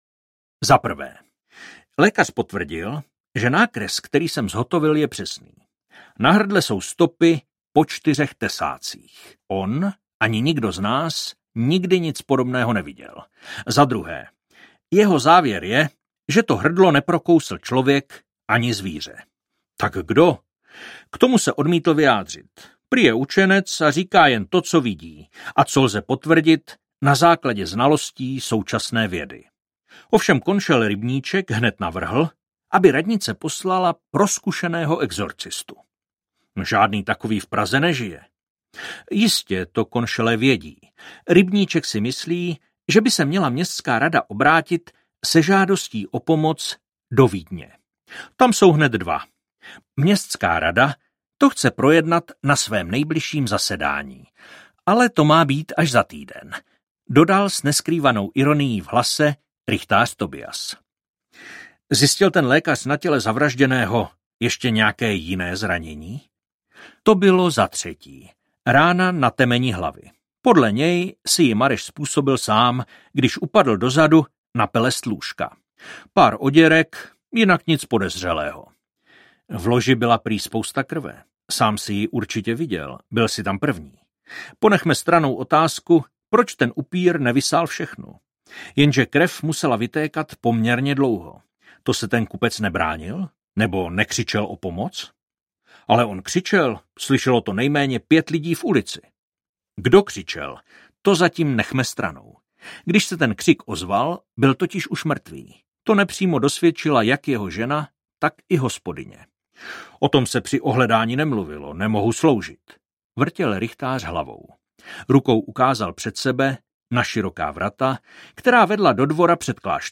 Pražský vampýr audiokniha
Ukázka z knihy
Vyrobilo studio Soundguru.